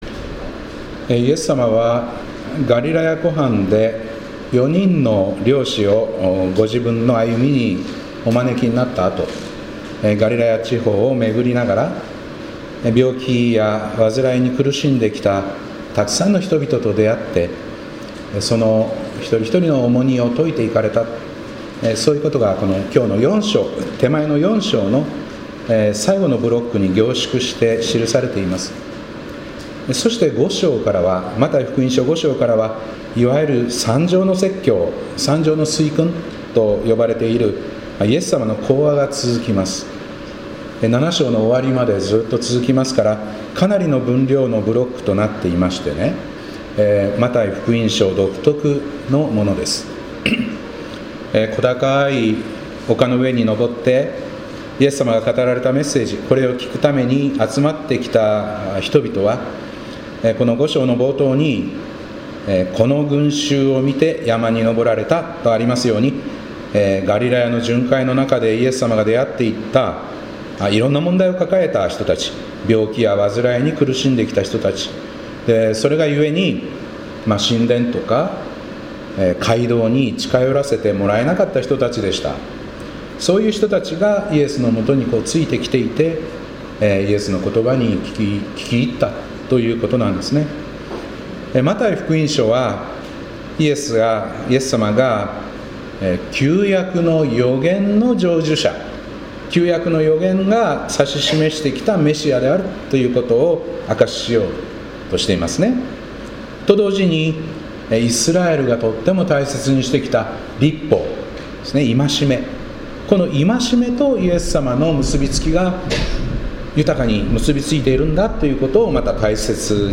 2025年1月19日礼拝「蒼天を仰ぐがごとく」